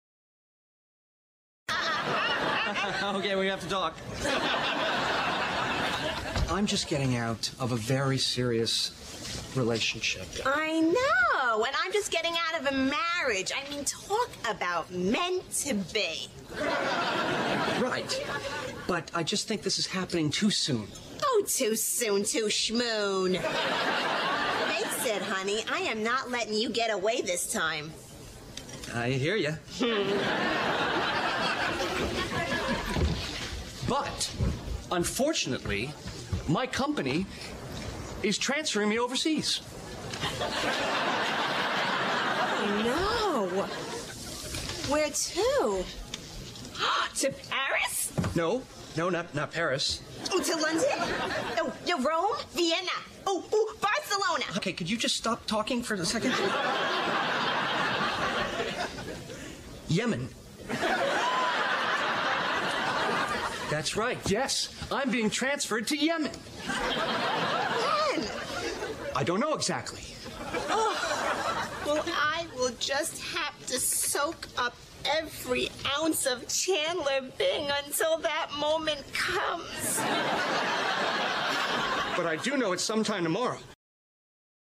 在线英语听力室影视剧中的职场美语 第112期:出差计划的听力文件下载,《影视中的职场美语》收录了工作沟通，办公室生活，商务贸易等方面的情景对话。